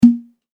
卒業証書入れポンッ1.mp3